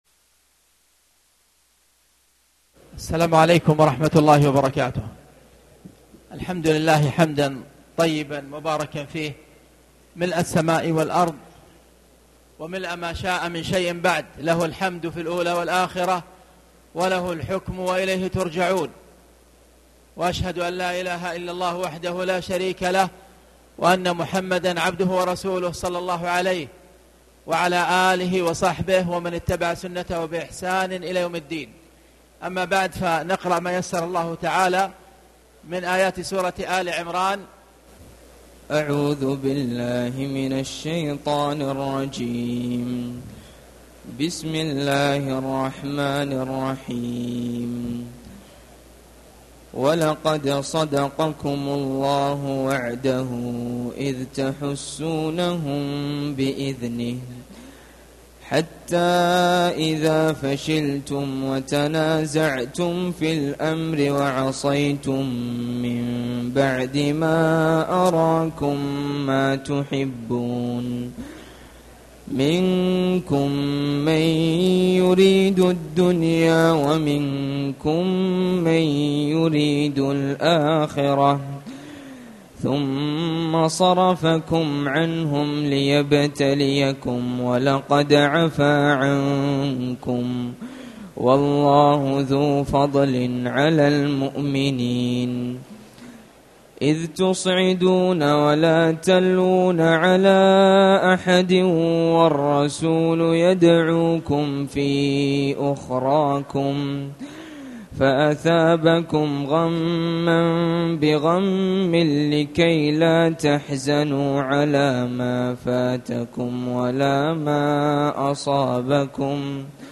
تاريخ النشر ١٩ رمضان ١٤٣٨ هـ المكان: المسجد الحرام الشيخ